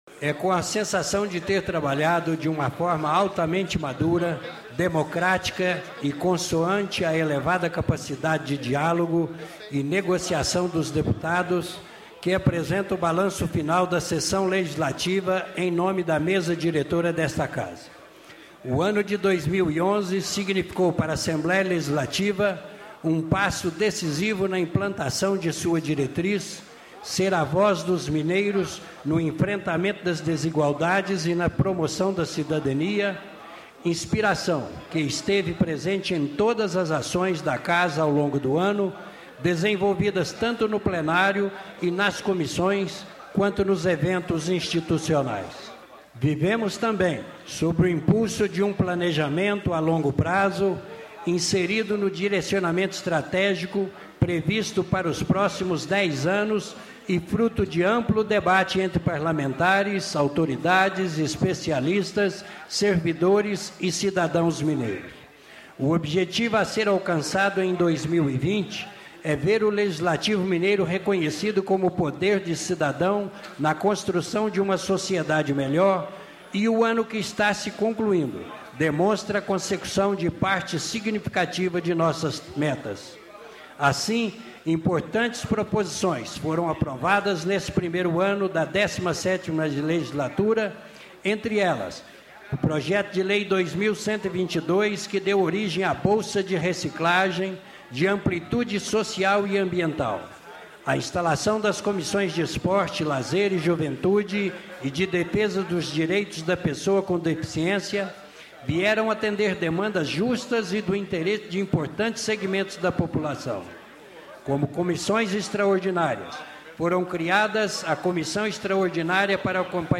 1º-secretário Dilzon Melo lê balanço das atividades da ALMG em 2011